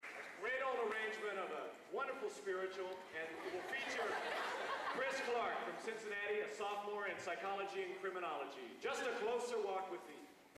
Location: Purdue Memorial Union, West Lafayette, Indiana
Genre: | Type: Director intros, emceeing